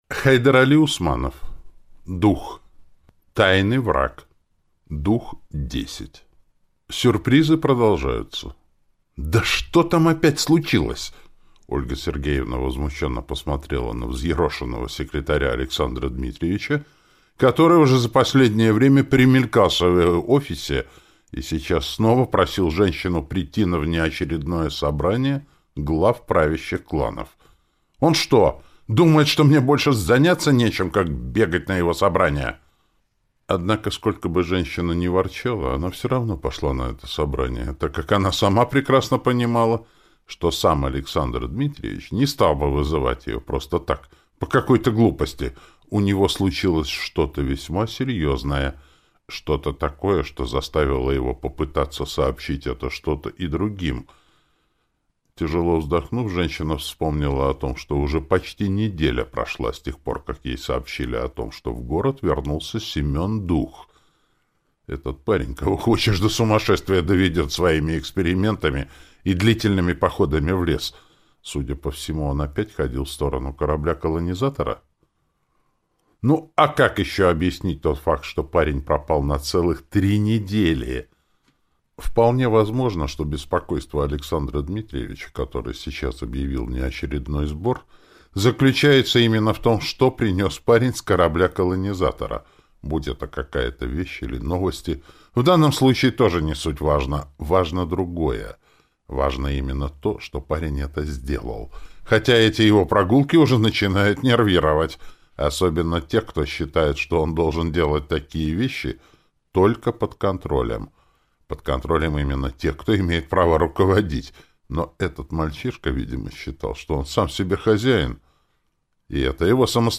Аудиокнига Дух. Тайный враг | Библиотека аудиокниг